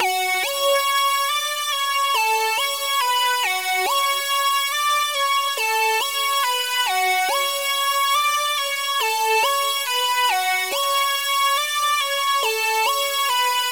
Trap Synth 140
描述：这是一个Trap合成器，也可以用于Dubstep，不需要署名。
Tag: 140 bpm Trap Loops Synth Loops 2.31 MB wav Key : Unknown